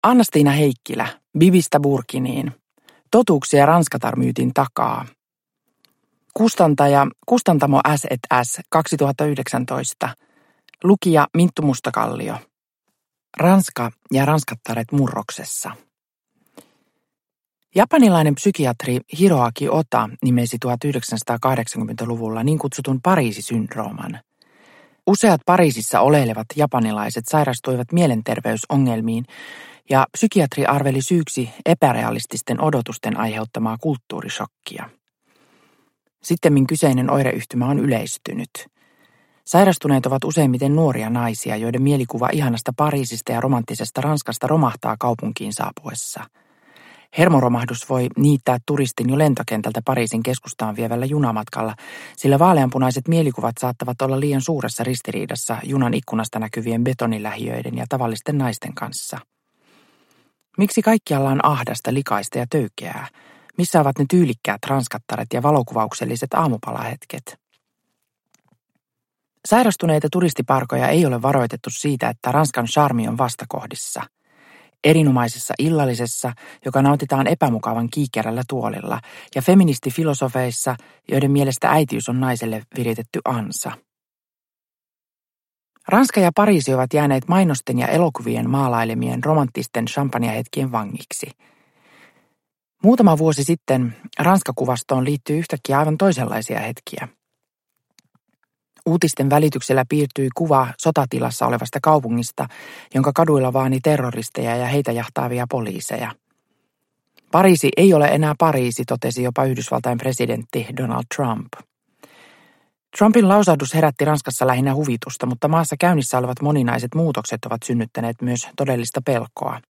Bibistä burkiniin – Ljudbok – Laddas ner
Uppläsare: Minttu Mustakallio